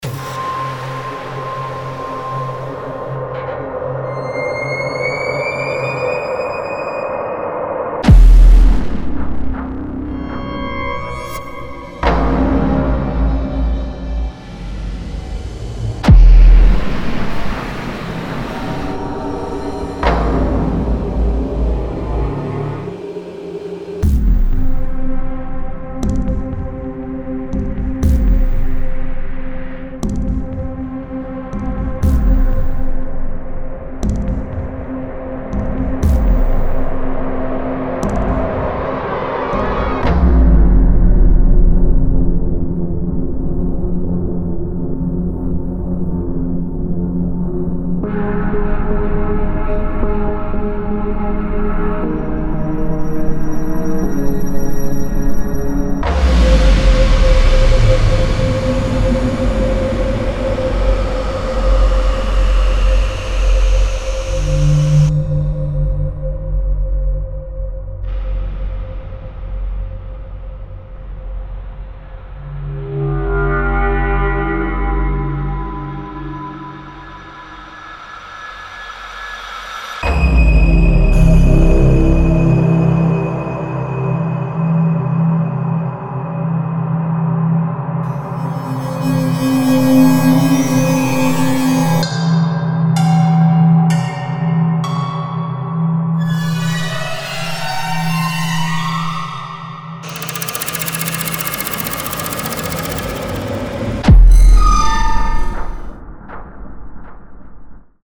深入研究深色的电影音效！
期待以电影为灵感的令人不安的氛围，史诗般的冲击，紧张的起伏，长时间困扰着的无人机，令人不安的噪音和故障，令人不安的垫子，噩梦般的合成器以及更多-创造令人激动和难忘的杰作所需的一切！
• 175 Foley Ambiences
• 93 Tonal Drones
• 10 Impacts